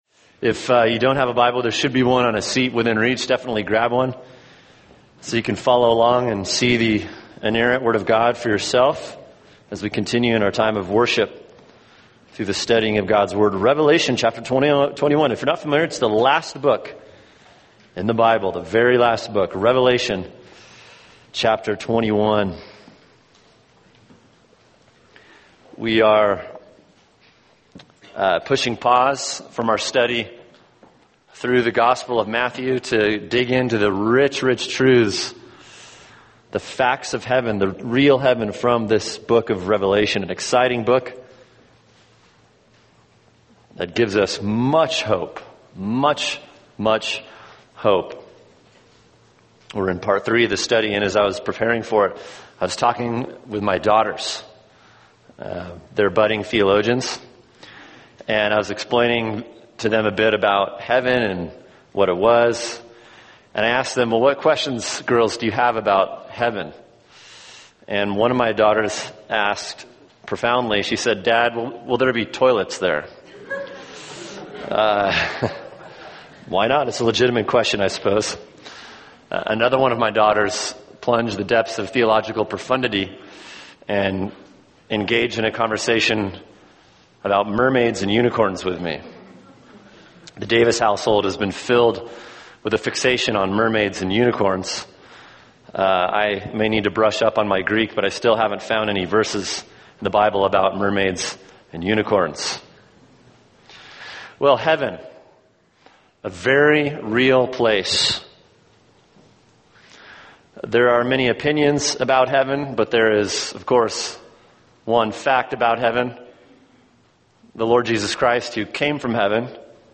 [sermon] Revelation 21 – All Things New (part 3) | Cornerstone Church - Jackson Hole